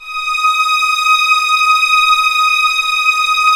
Index of /90_sSampleCDs/Roland LCDP13 String Sections/STR_Violins IV/STR_Vls7 p%f St